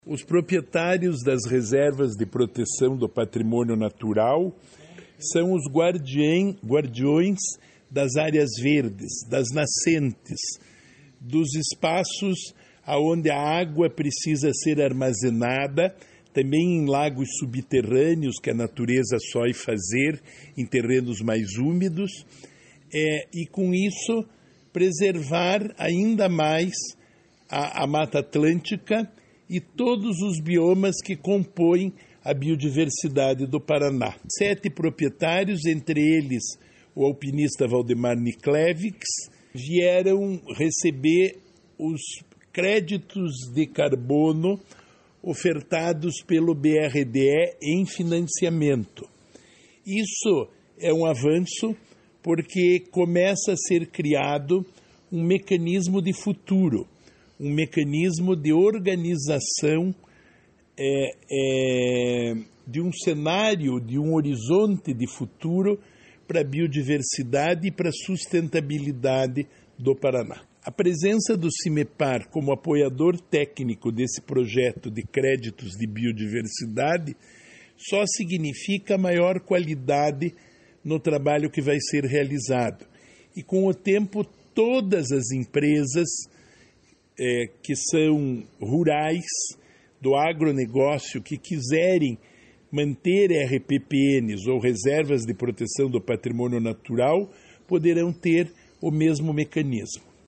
Sonora do secretário do Desenvolvimento Sustentável, Rafael Greca, sobre compromissos com RPPNs no projeto de créditos de biodiversidade